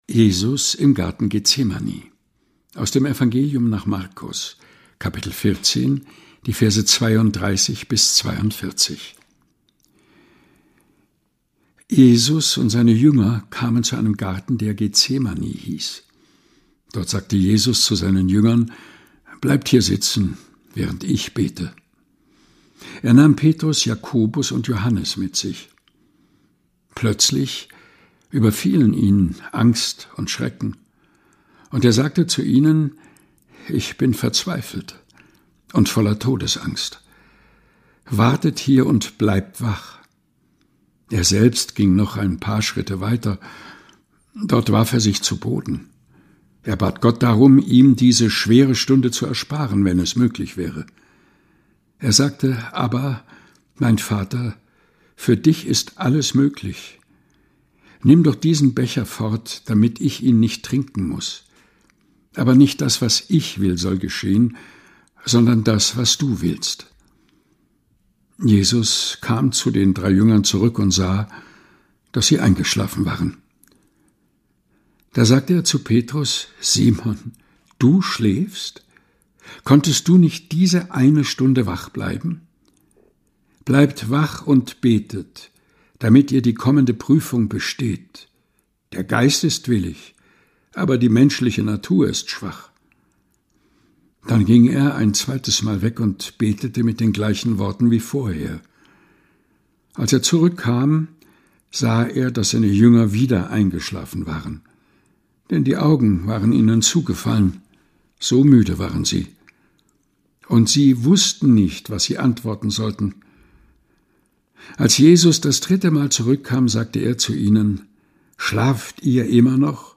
liest: Jesus im Garten Getsemani (Markus 14,32-42).